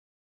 Play Explosion Tulun - SoundBoardGuy
Play, download and share explosion tulun original sound button!!!!
tulun-explosion-xd.mp3